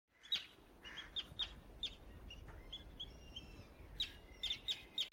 دانلود آهنگ پرنده 21 از افکت صوتی انسان و موجودات زنده
دانلود صدای پرنده 21 از ساعد نیوز با لینک مستقیم و کیفیت بالا
جلوه های صوتی